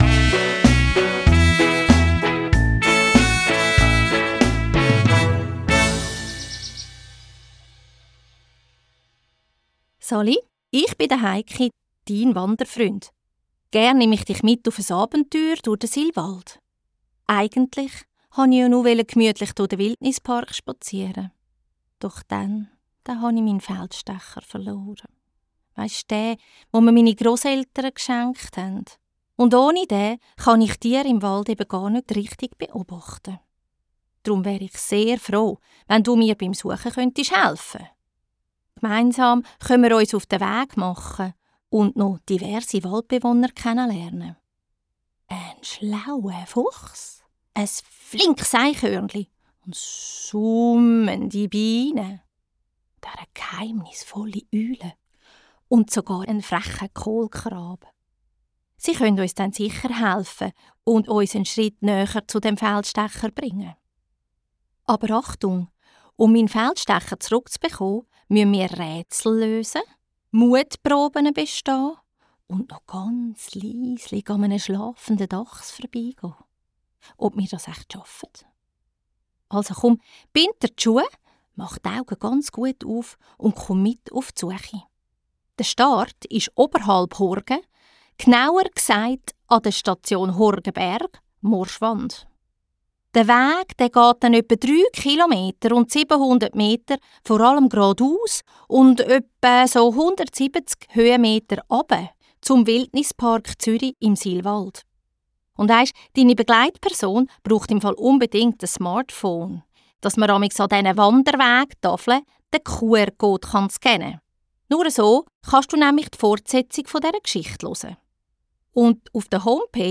Im Tonstudio